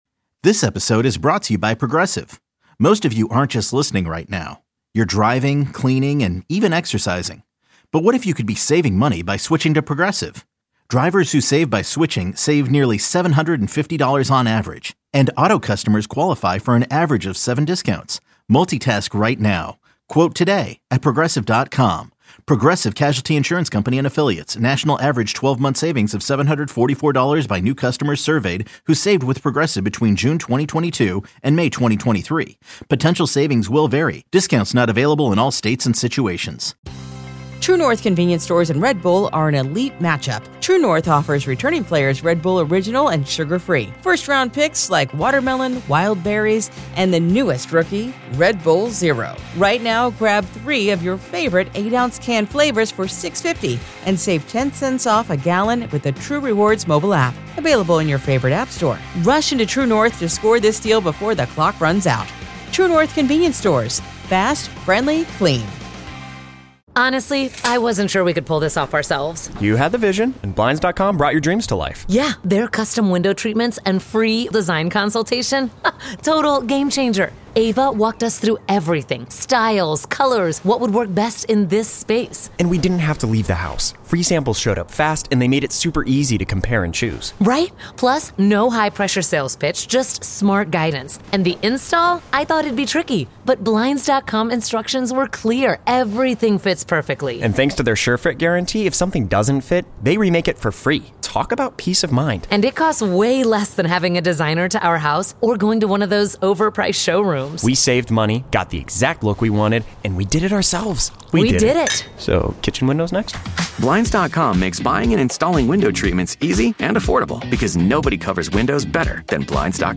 Callers weigh in on the importance of Alex Bregman to the team.